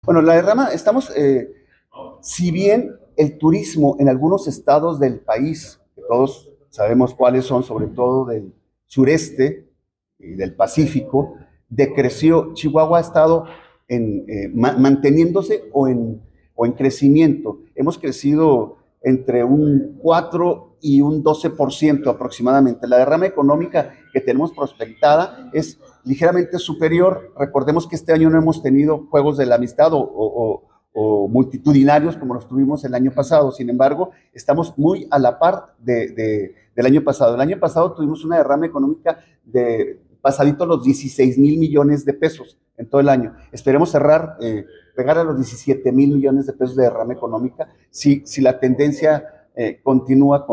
AUDIO: EDIBRAY GÓMEZ, SECRETARIO DE TURISMO ESTATAL 1